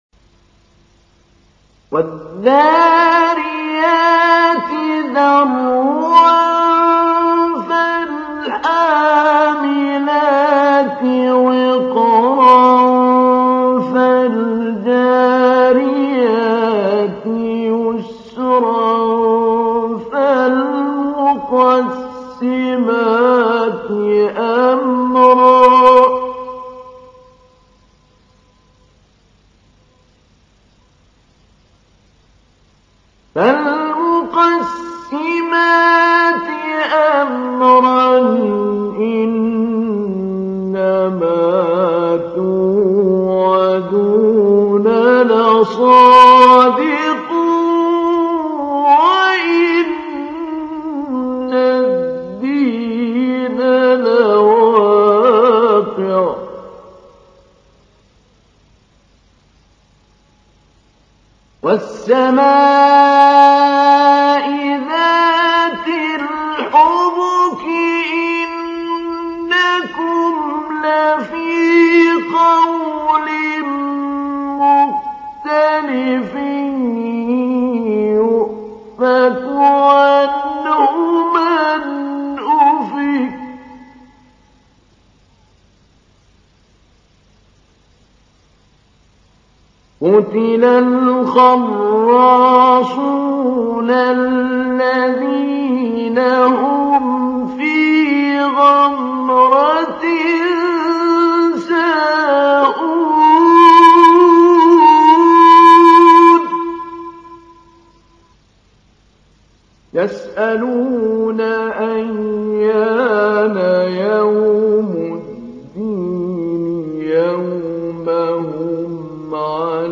تحميل : 51. سورة الذاريات / القارئ محمود علي البنا / القرآن الكريم / موقع يا حسين